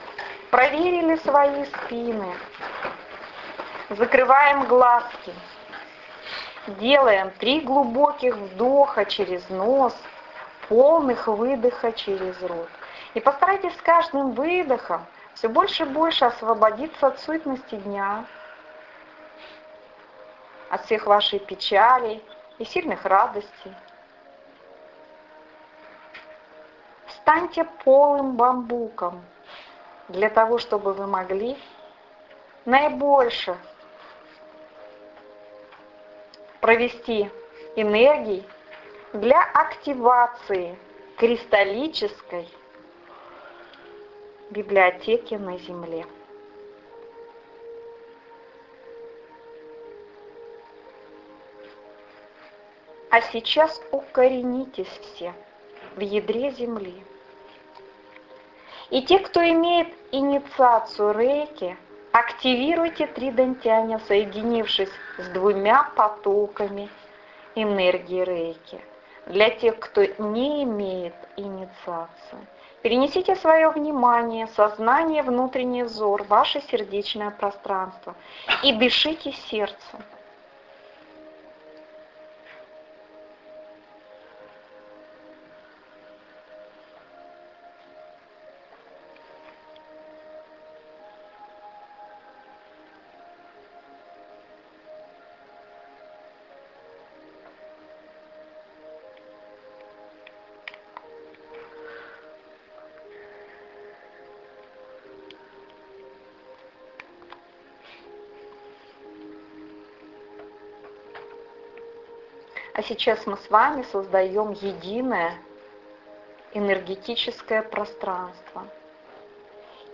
Активация Кристаллической Библиотеки на Земле Аудиомедитация Здравствуй моя Духовная Семья!!!